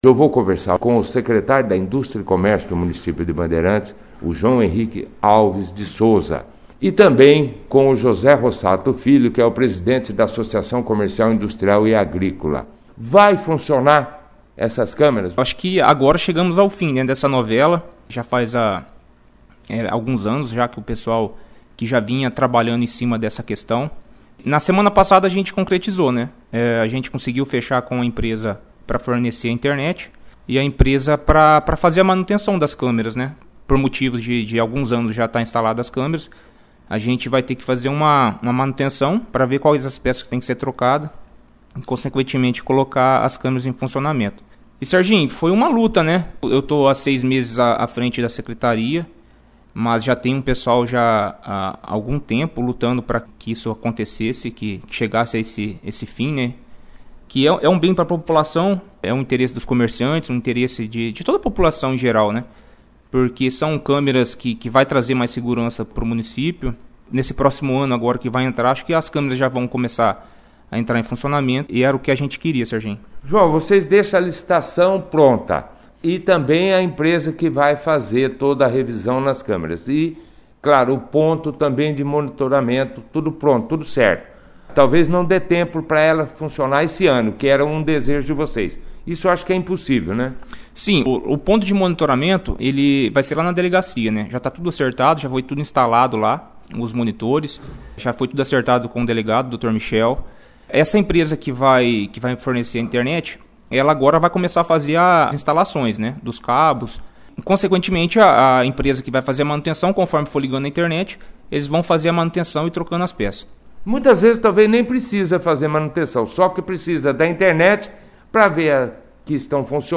participaram da 2ª edição do jornal Operação Cidade desta terça-feira, 22/12, falando sobre o acordo, em fim fechado, para manutenção das câmeras de segurança e fornecimento de internet, para monitoramento das ruas da cidade e de quando realmente estará em funcionamento.